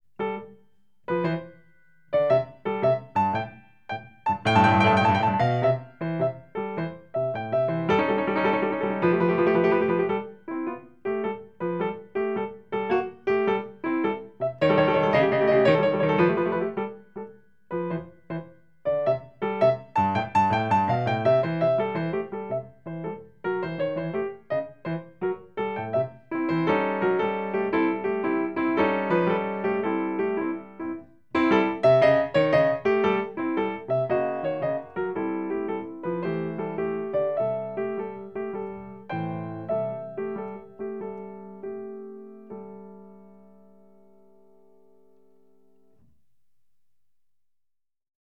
Solos piano